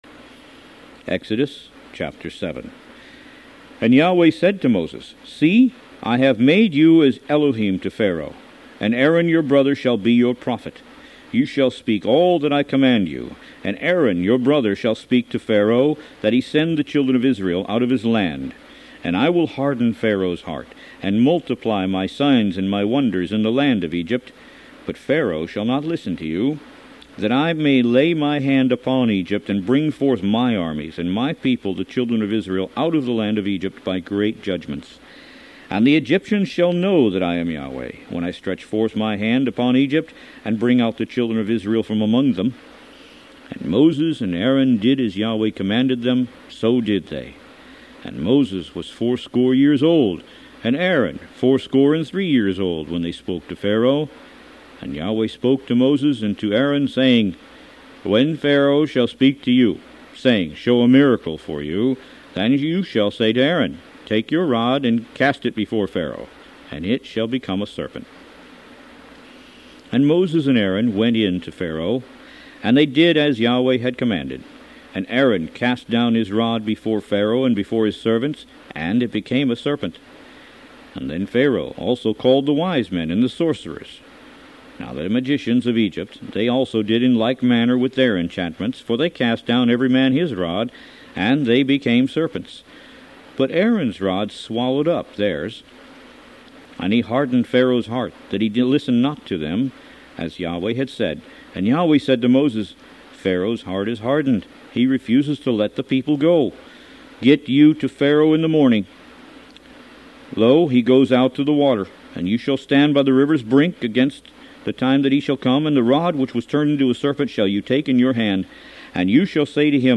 Root > BOOKS > Biblical (Books) > Audio Bibles > Tanakh - Jewish Bible - Audiobook > 02 Exodus